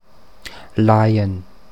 Standarddeutsche Form
[laiən]